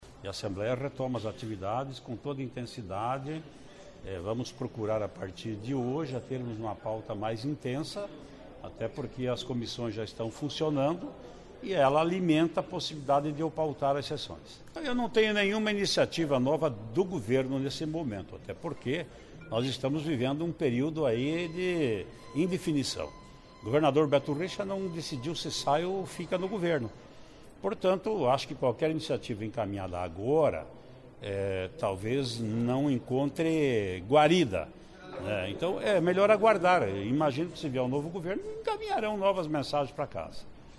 Ouça entrevista com presidente da Assembleia Legislativa.